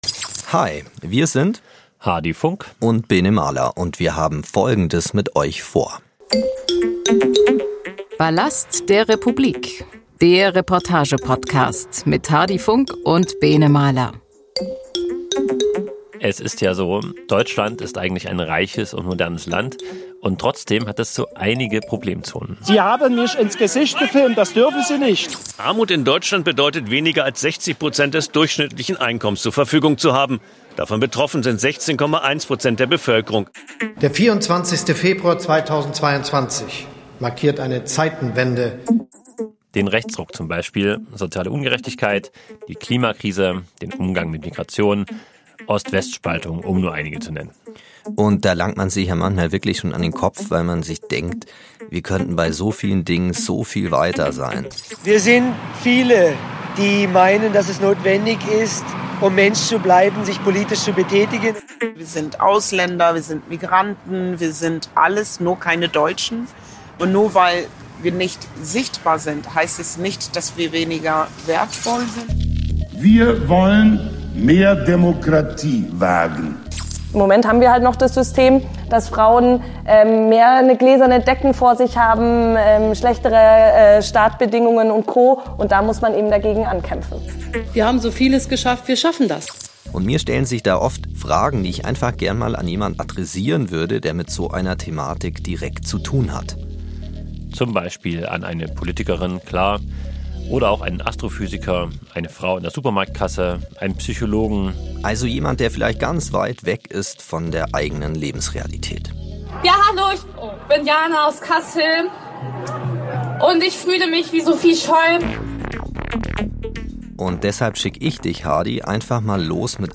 Der Reportage Podcast